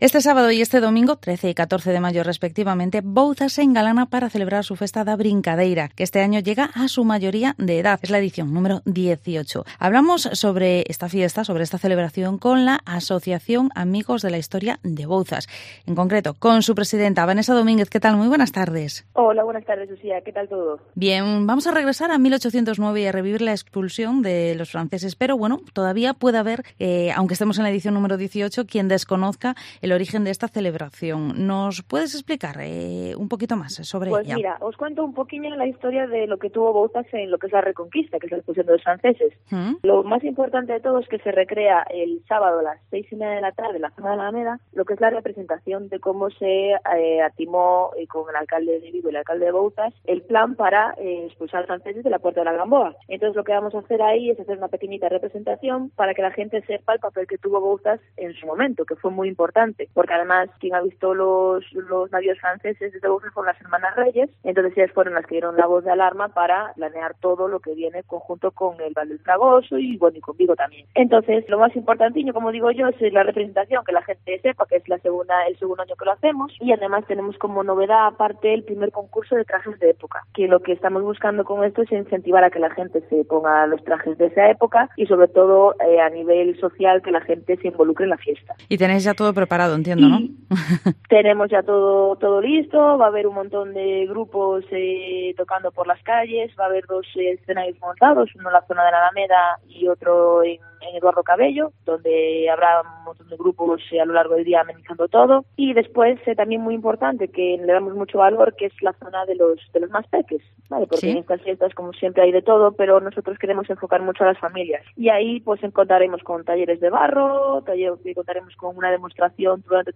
Vigo Entrevista XVIII Festa da Brincadeira en Bouzas.